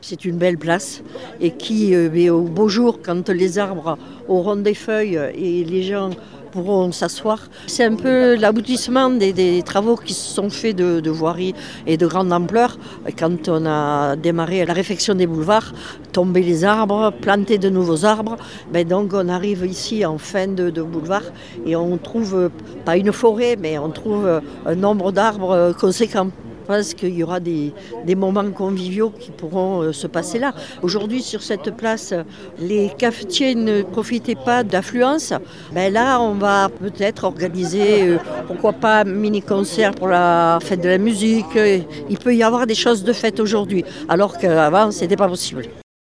Hier matin, de nombreux élus étaient réunis devant la mairie pour inaugurer la nouvelle place Charles de Gaulle. Mille mètres carrés d’espaces verts, davantage de place pour les piétons et un aménagement destiné à faire ralentir les automobilistes sur la RN 88. Une rénovation réussie pour la maire de Mende.